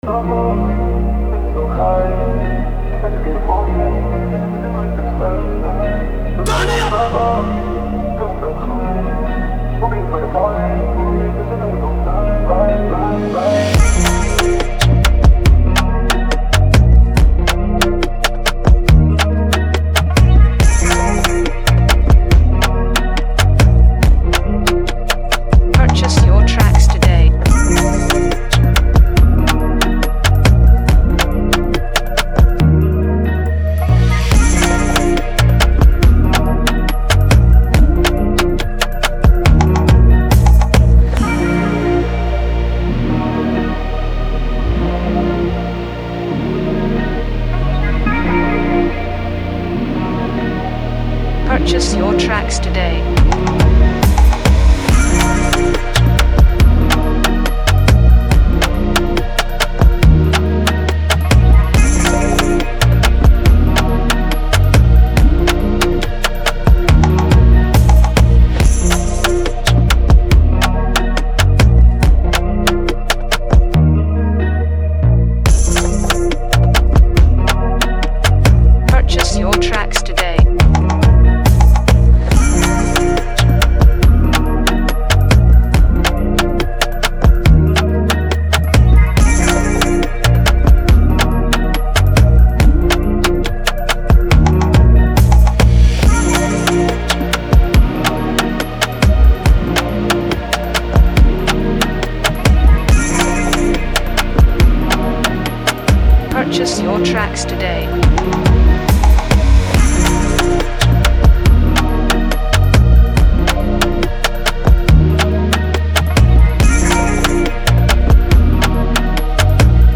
an electrifying Afro Drill instrumental
With a brisk 140 BPM